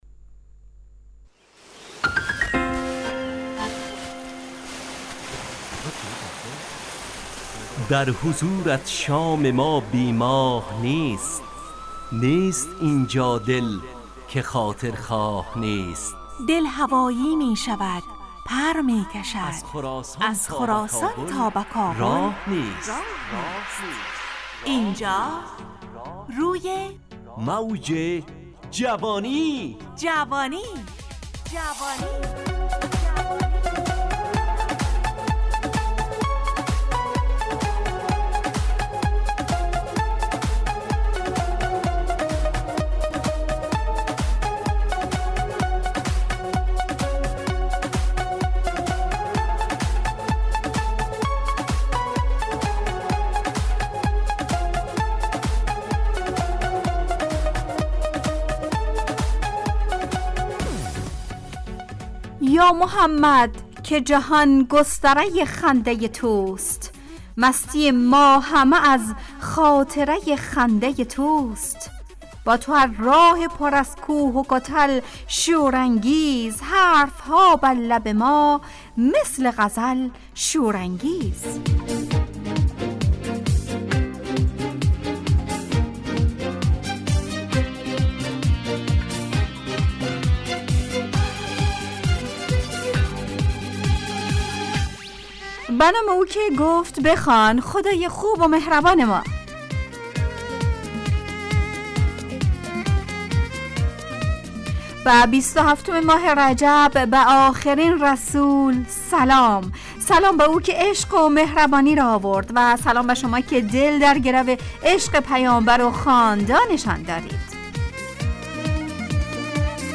روی موج جوانی، برنامه شادو عصرانه رادیودری.
همراه با ترانه و موسیقی مدت برنامه 55 دقیقه .